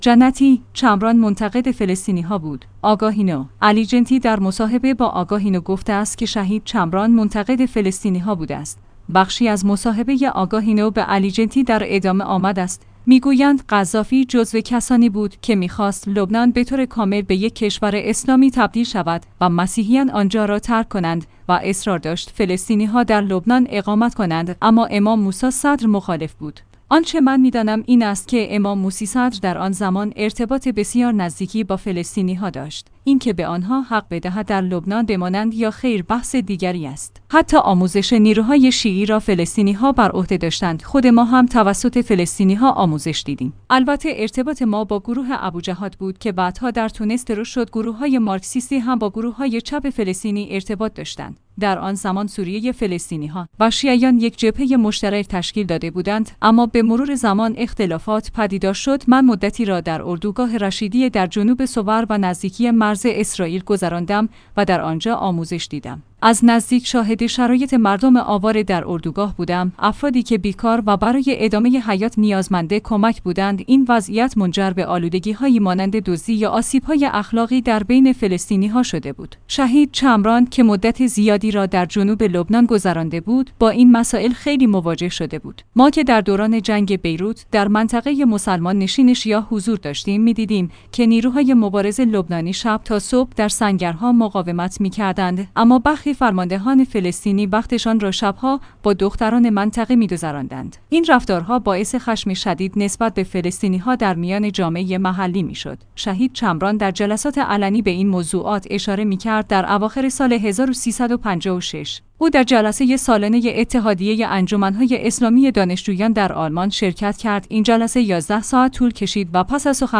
آگاهی نو/ علی جنتی در مصاحبه با آگاهی نو گفته است که شهید چمران منتقد فلسطینی‌ها بوده است.